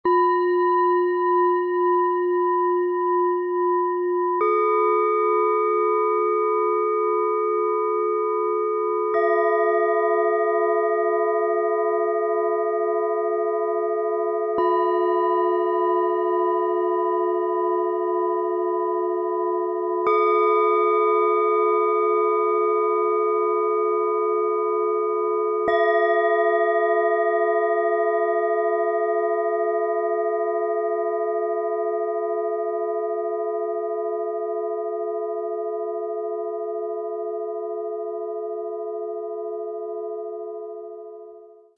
Himmlisches Jubeln: Heiterkeit, Leichtigkeit, innere Weite – Set aus 3 Klangschalen, Ø 10,3 - 13,7 cm, 1,25 kg
Dieses Set strahlt freundliche Heiterkeit, ausgleichende Ruhe und lichtvolle Leichtigkeit aus. Es jubiliert, erhebt und schenkt ein Gefühl innerer Weite – wie ein Klang, der lächelt und aufsteigt.
Ein sanfter, ausgleichender Klang, der innere Ruhe bringt.
Dieser Ton jubiliert in freundlicher Gelassenheit.
Ein hoher, fast ätherischer Ton, der nach oben zieht.
Lauschen Sie den freundlich-heiteren Klängen mit lichtvoller Leichtigkeit und tiefer Ruhe.
Lassen Sie die Klangschalen mit dem kostenlosen Klöppel sanft erklingen und erleben Sie die heiter-leichten Schwingungen dieses besonderen Sets.
MaterialBronze